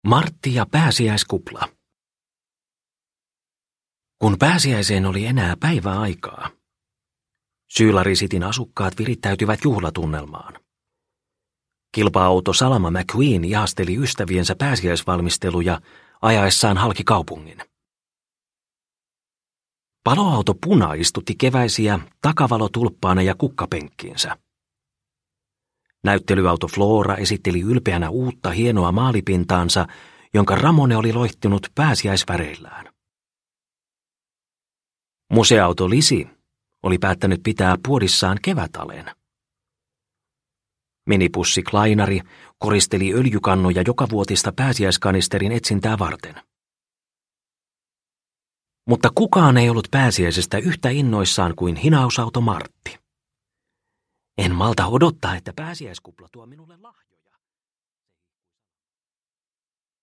Pixar Autot. Martti ja pääsiäiskupla – Ljudbok – Laddas ner